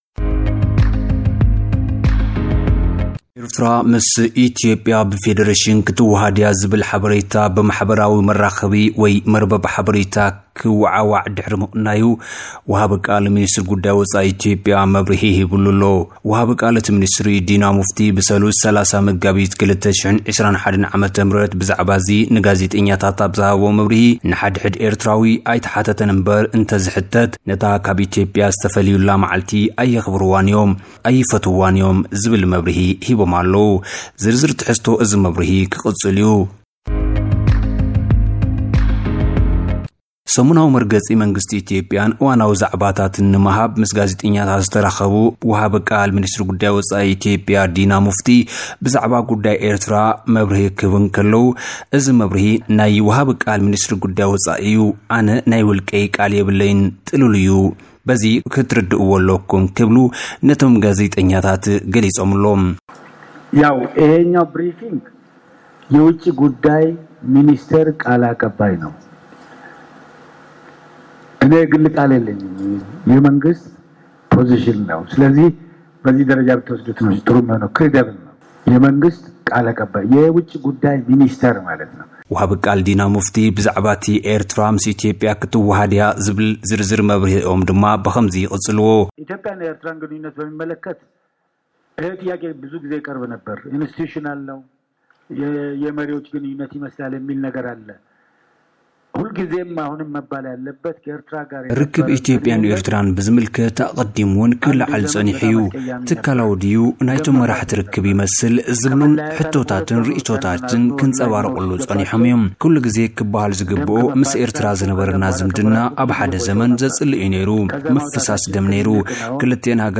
እዚ ወግዓዊ መግለጺ ሚ/ሪ ጉዳያት ወጻኢ ኢትዮጵያ ብ ኣፈኛኡ ዲና ሙፍቲ ዝተዋህበ መግለጺ ኢዩ፣